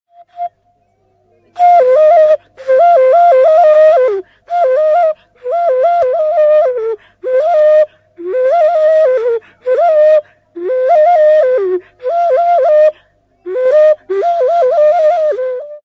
TR204-15.mp3 of Chigowilo Ocarina tune